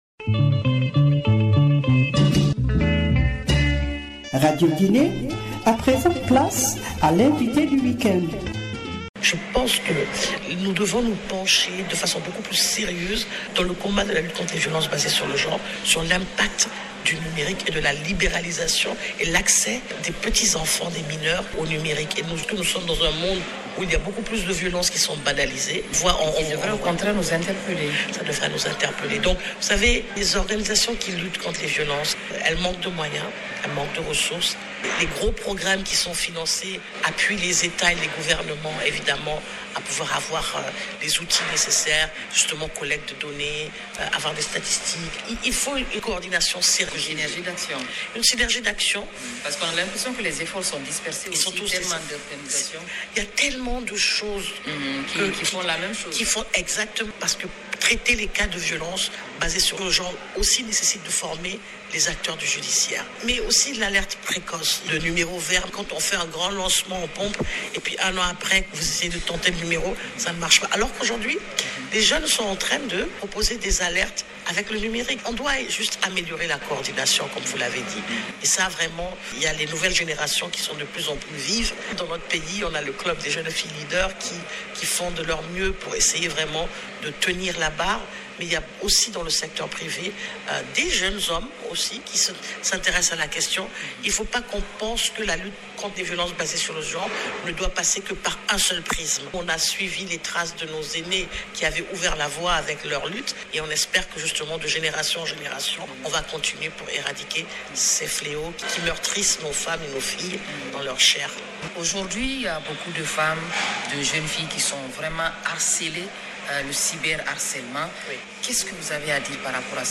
Invité du week-end